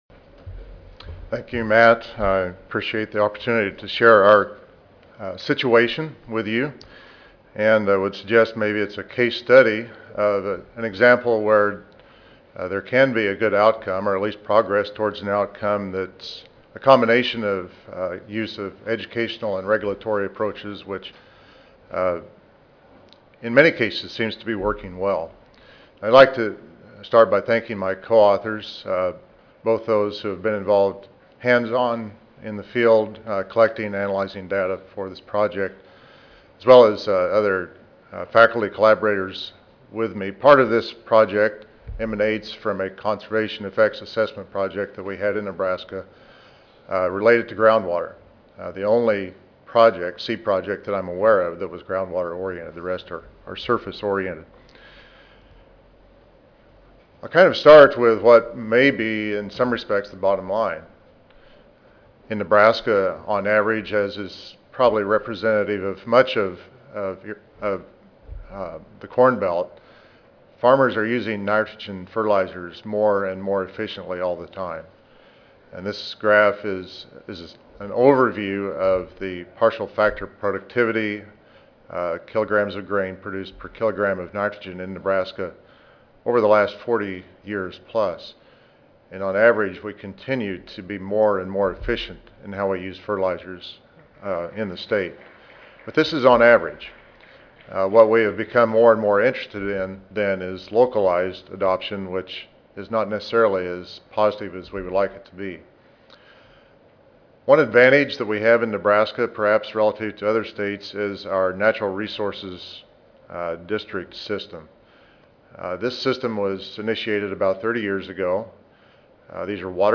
University of Nebraska Audio File Recorded presentation